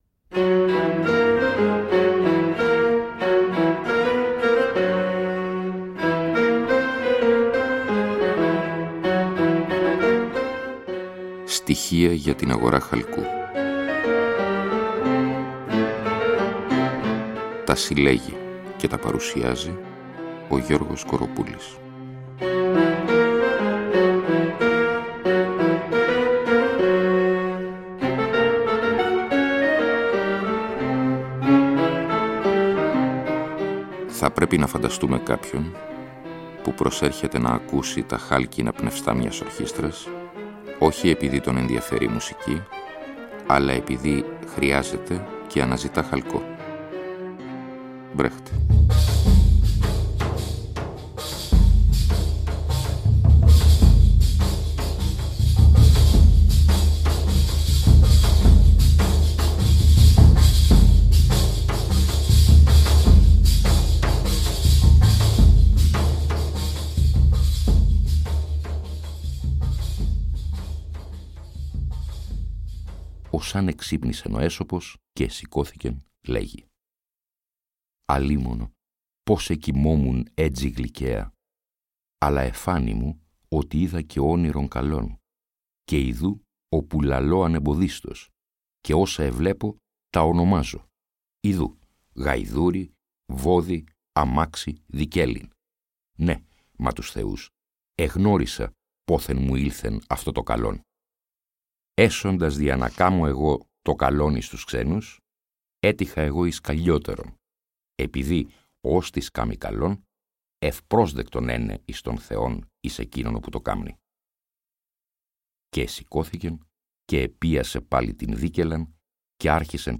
Εκπομπή λόγου.